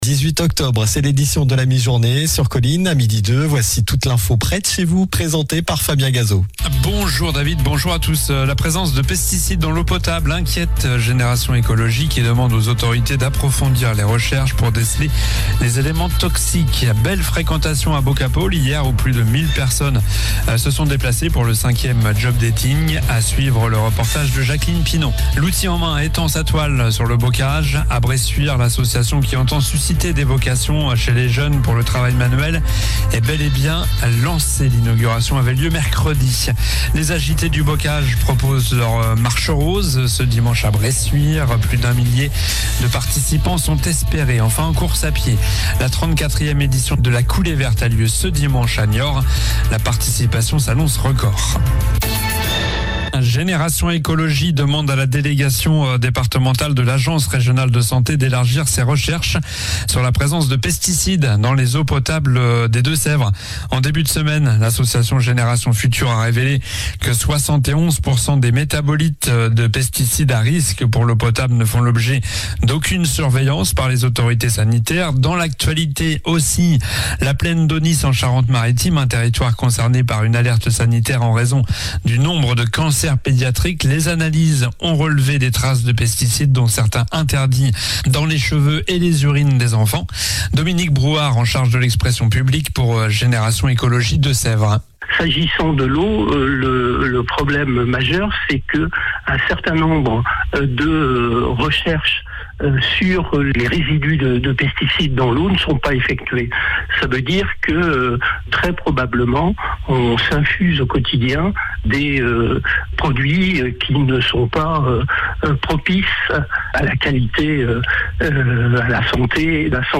Journal du vendredi 18 octobre (midi)